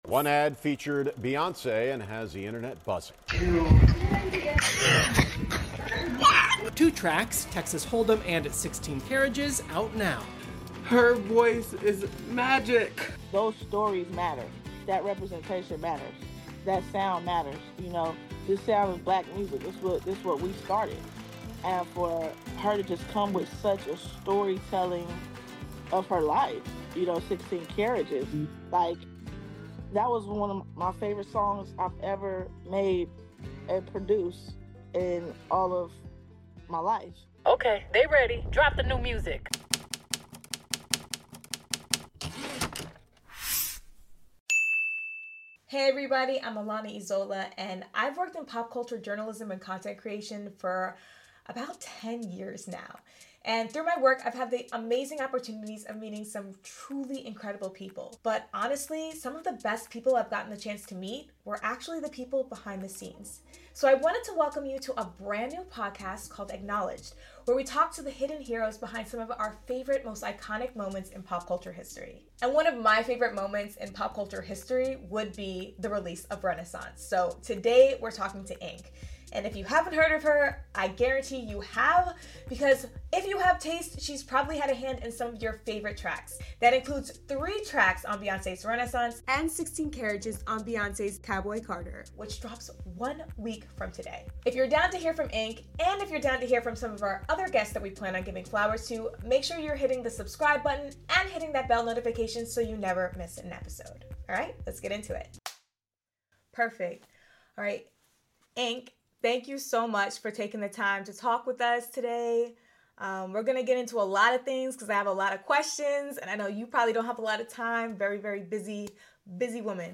Music Interviews